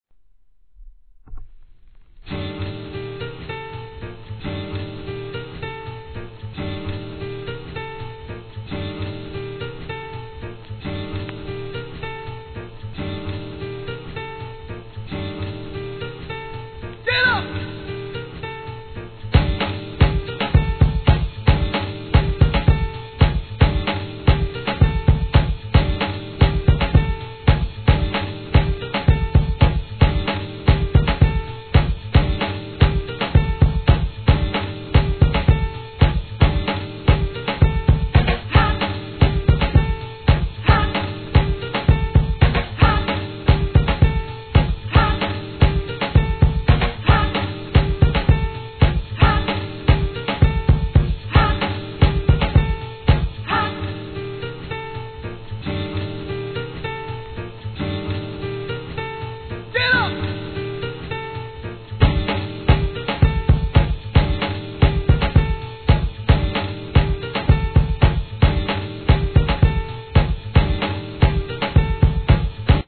大ヒットとなったJAZZYブレークビーツ!!